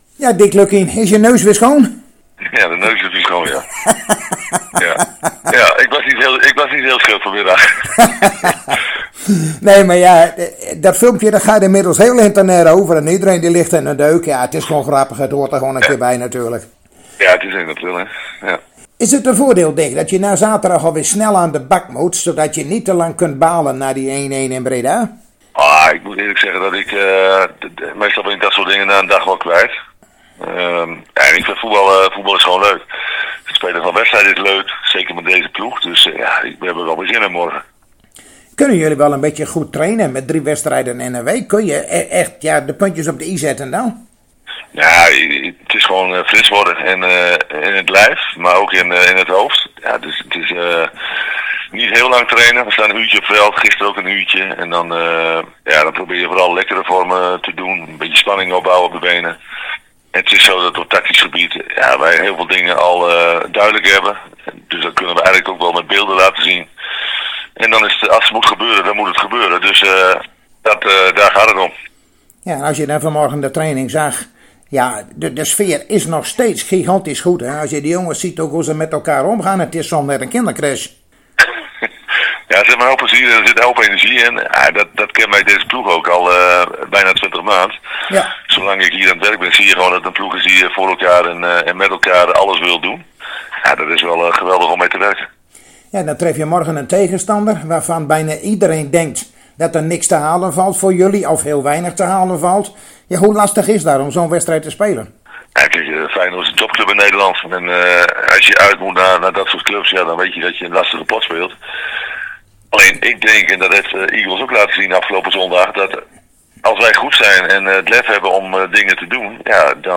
Zojuist spraken wij weer met trainer Dick Lukkien van FC Groningen over de wedstrijd van morgen die zijn ploeg speelt in De Kuip tegen Feyenoord.